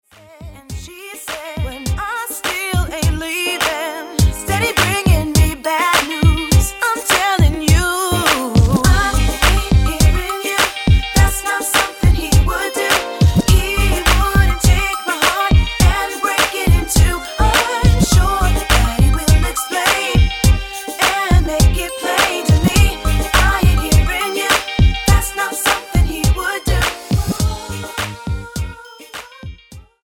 NOTE: Background Tracks 1 Thru 9